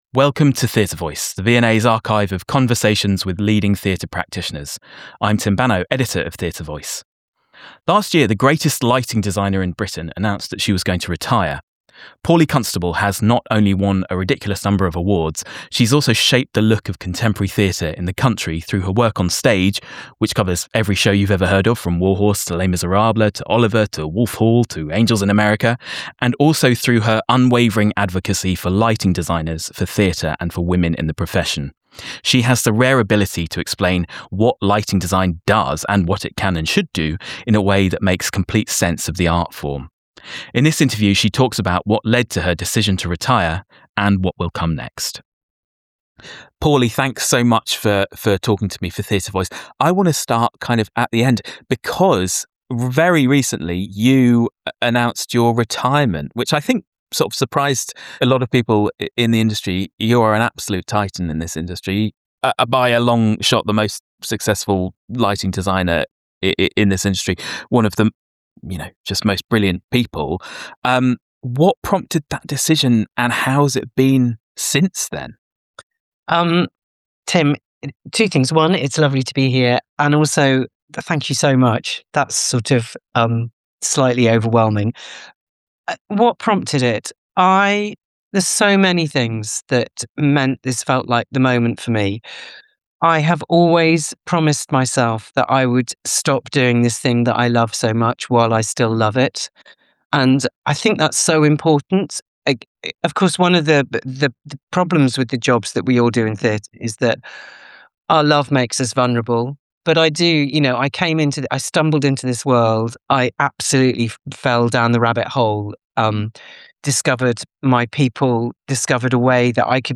In this interview she talks about what led to her decision to retire in 2025, and what will come next. Recorded on Zoom, 22nd October 2025